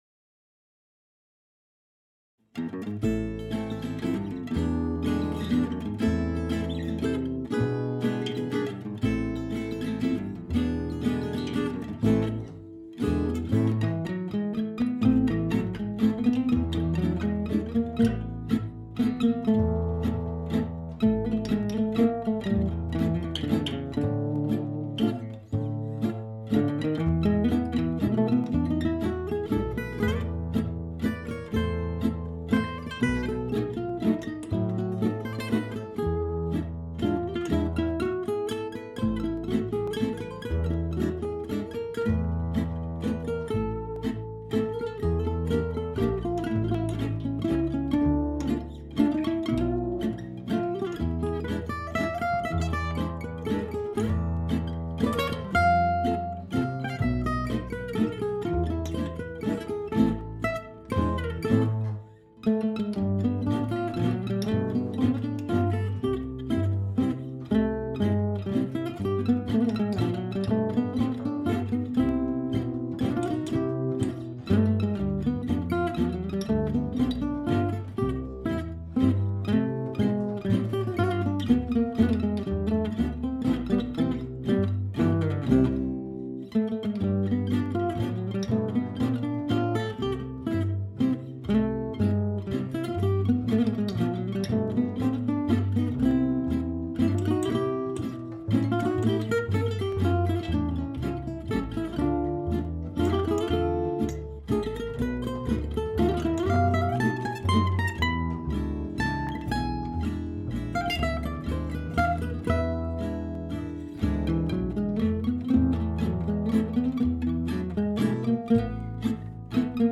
A little chord study:
Brought it home (on the bike: guitar in one hand, the other on the handlebar - there was an old case, but it smelled rather musty), cleaned it, treated it with care oil, changed strings and recorded the study with my mobile phone.